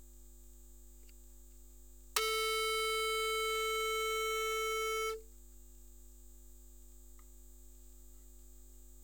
Ecouter la première note la 4 jouée par les différents instruments.
le diapason